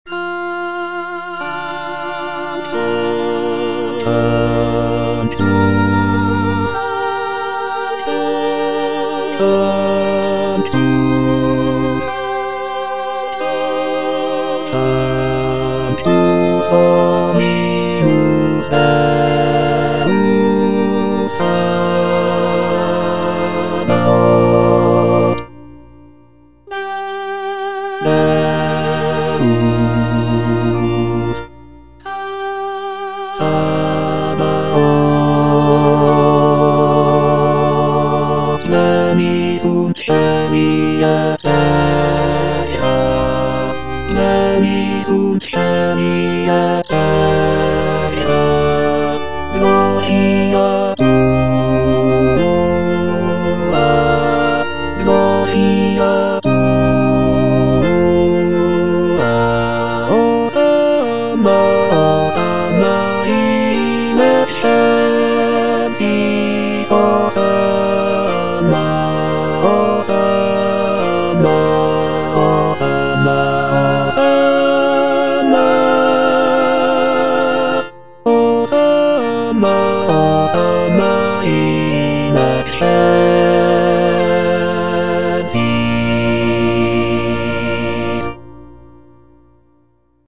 T. DUBOIS - MESSE IN F Sanctus - Bass (Emphasised voice and other voices) Ads stop: auto-stop
It is a setting of the traditional Catholic Mass text in the key of F major. The piece is known for its lush harmonies, intricate counterpoint, and lyrical melodies.